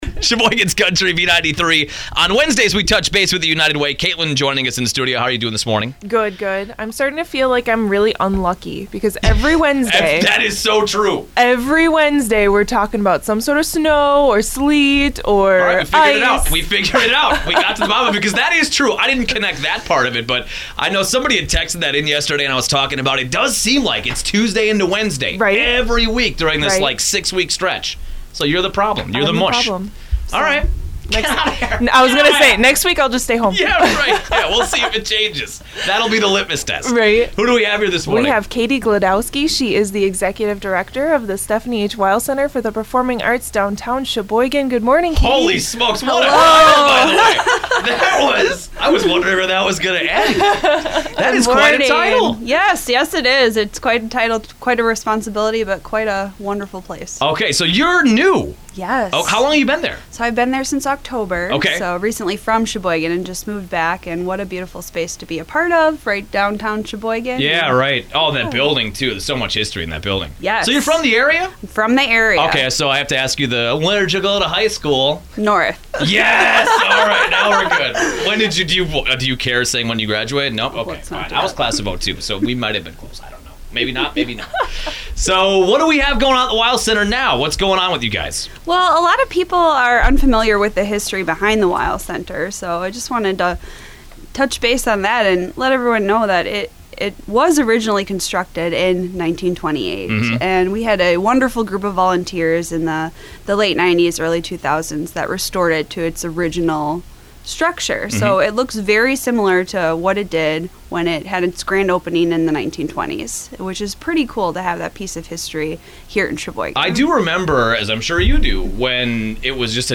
Weill Center - Radio Spot